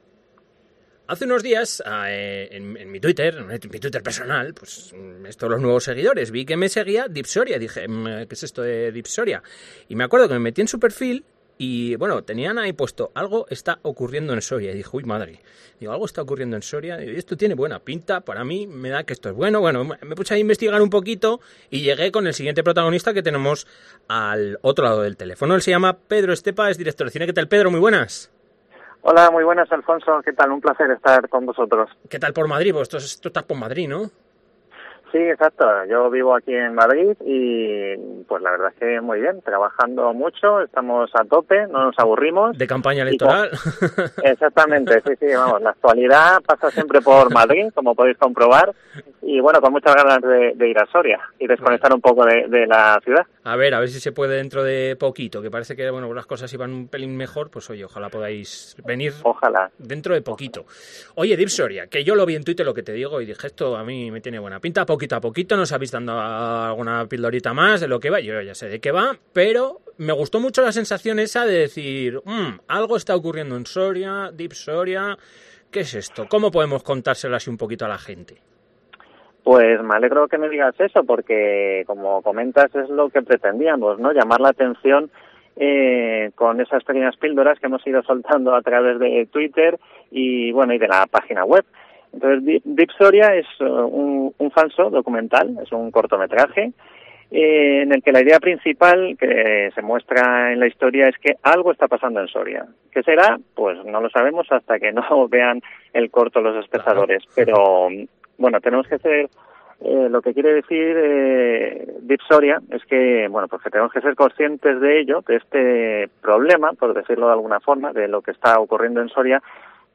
ENTREVISTA.